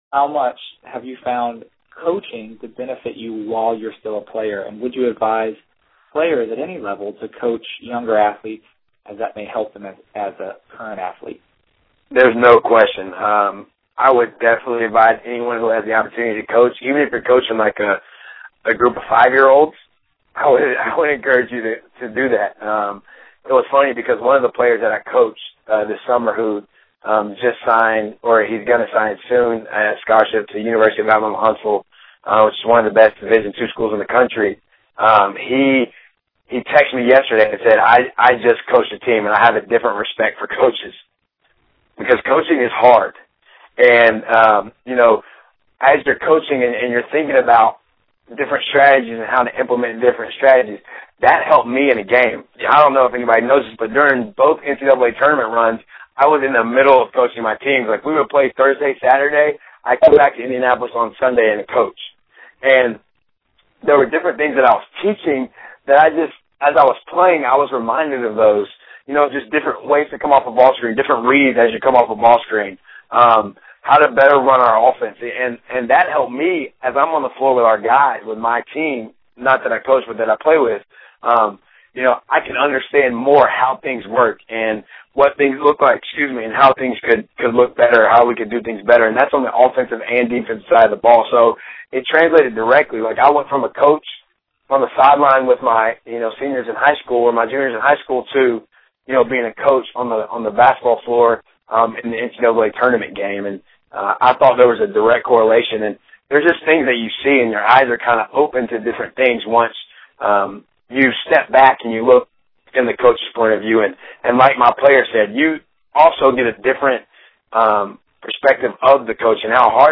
My hope is that you all found and will find value in the PGC Online Training quarterly interviews with college athletes and coaches while you are in the midst of training in order to reach your basketball aspirations.